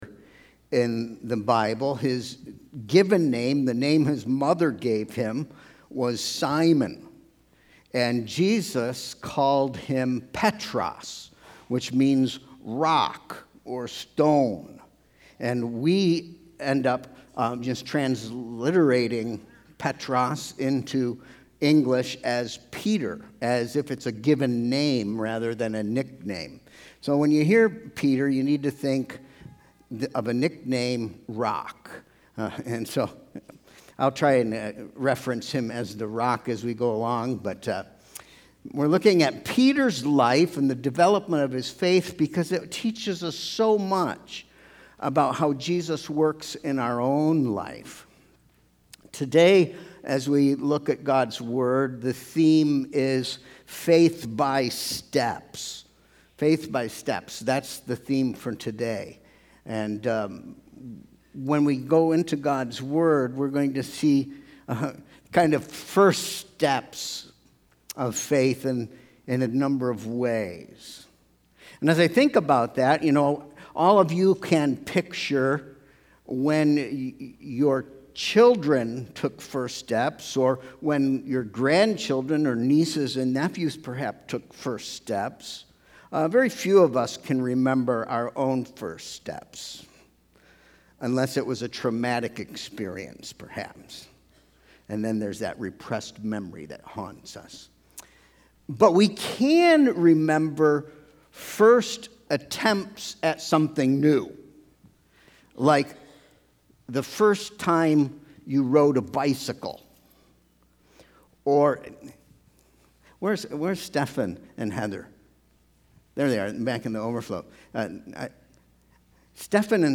Sermon Proposition: Jesus is growing your faith step by step.